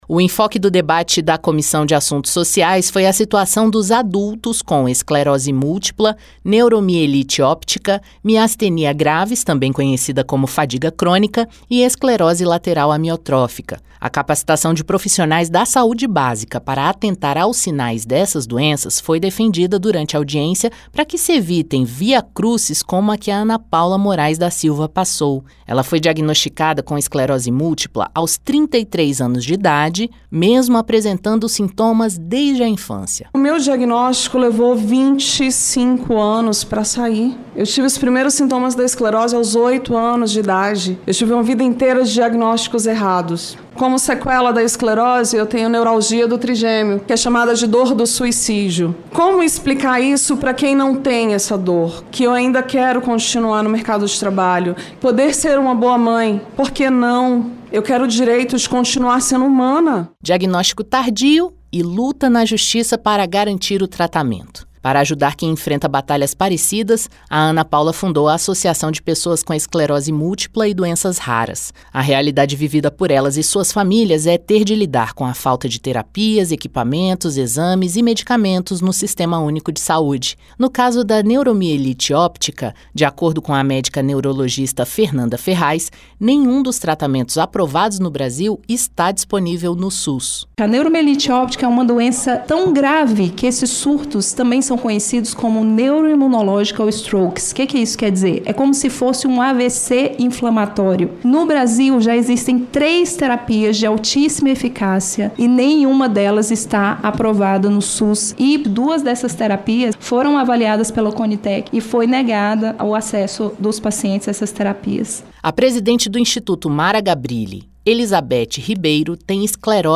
Durante audiência na Comissão de Assuntos Sociais (CAS), nesta segunda-feira (11), portadores de doenças raras como esclerose múltipla, neuromielite óptica e esclerose lateral amiotrófica (ELA), entre outras, apresentaram relatos das dificuldades e dos desafios quem enfrentam devido a sua condição. Os especialistas presentes no debate defenderam uma série de medidas, como a capacitação dos profissionais de saúde para atender esses pacientes e o diagnóstico precoce — para que haja o tratamento adequado.